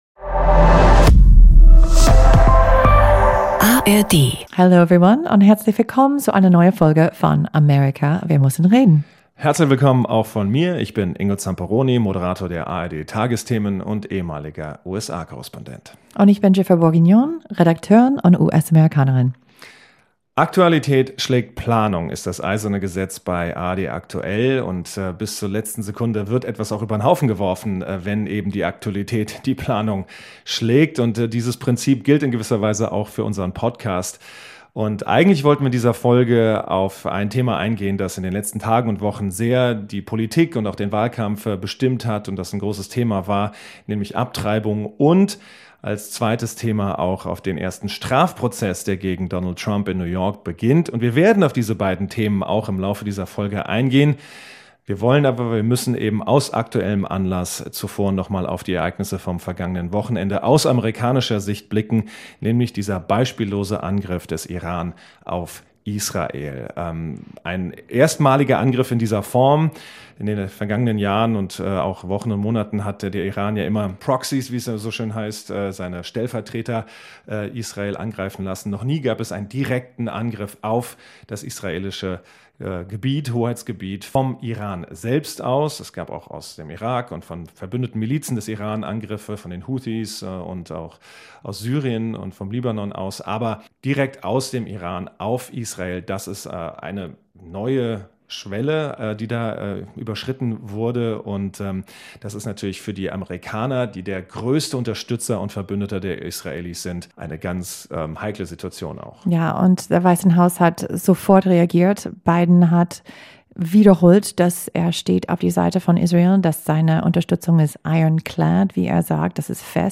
Zudem erörtern die beiden Journalisten, wie sich die USA nach der jüngsten Eskalation in Nahost positionieren.